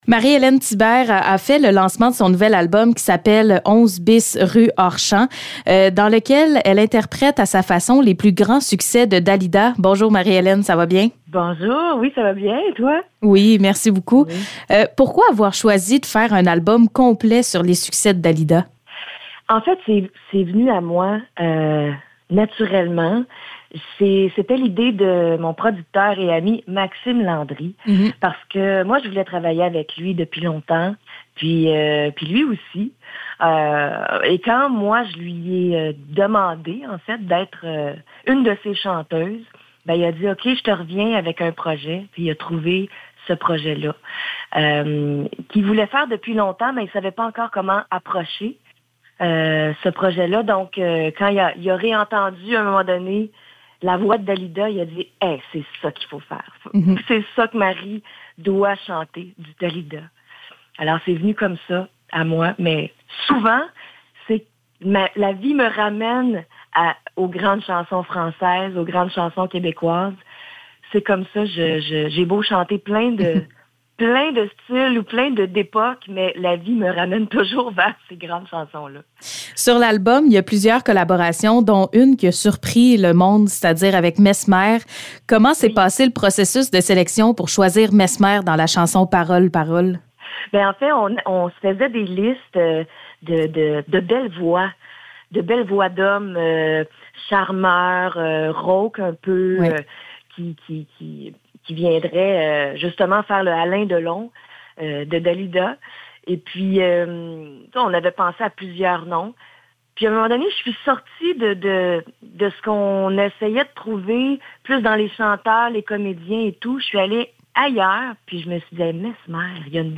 Entrevue avec Marie-Élaine Thibert